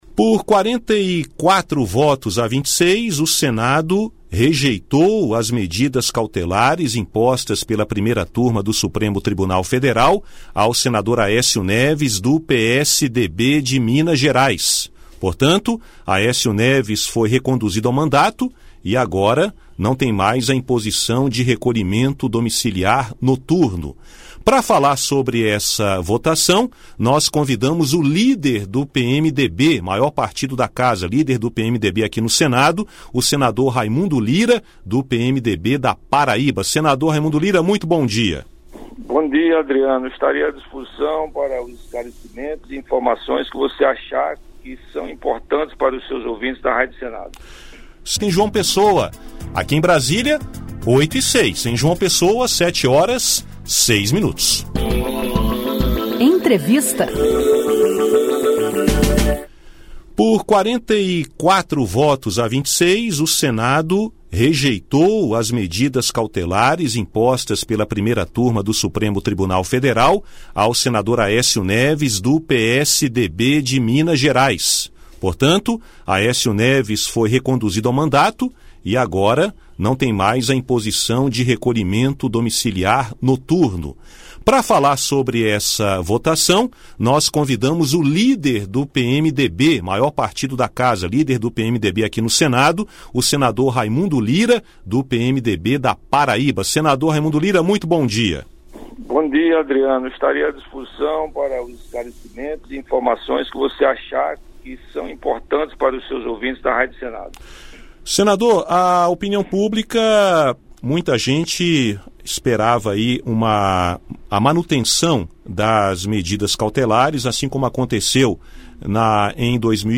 Líder do PMDB no Senado, o senador Raimundo Lira (PB) afirmou, em entrevista à Rádio Senado, que a rejeição das medidas cautelares impostas pelo Supremo Tribunal Federal (STF) ao senador Aécio Neves (PSDB-MG) fortalece a democracia. Para ele, a decisão do Senado reforça o sistema republicano, de independência dos poderes Executivo, Legislativo e Judiciário.